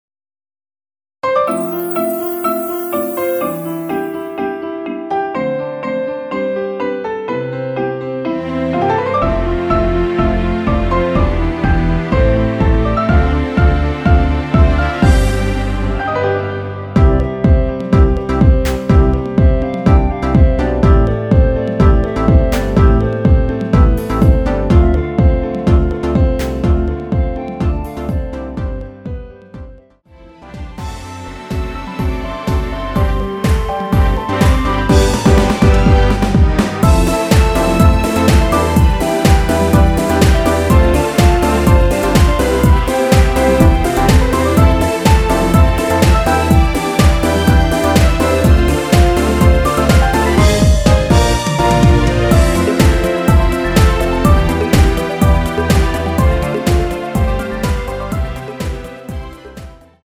엔딩이 페이드 아웃이라 엔딩을 만들어 놓았습니다.
원키 멜로디 포함된 MR입니다.
앞부분30초, 뒷부분30초씩 편집해서 올려 드리고 있습니다.